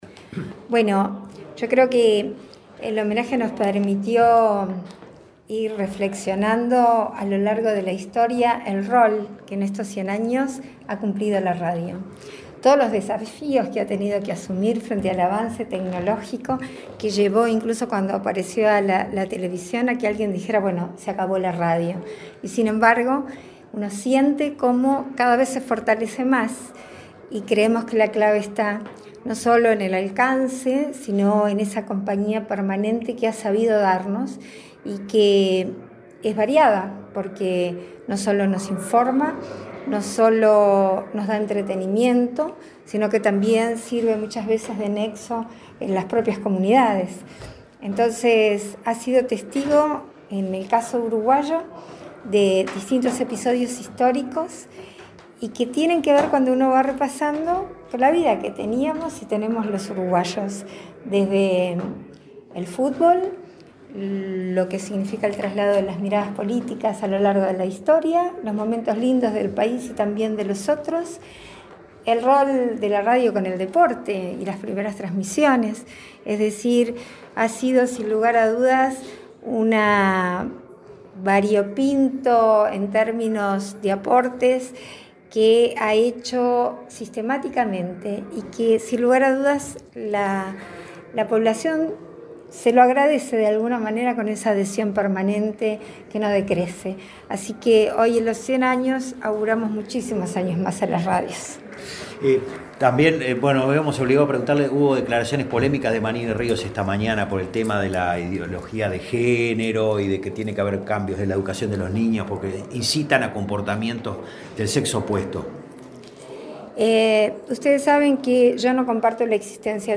Declaraciones de la vicepresidenta de la República, Beatriz Argimón
Luego la jerarca dialogó con la prensa.